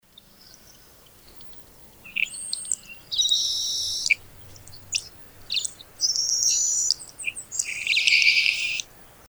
Saltmarsh Sparrow
The saltmarsh sparrow has a quiet song including a variety of high-pitched trills and clicks.
Saltmarsh_Sparrow_Bird_Call.mp3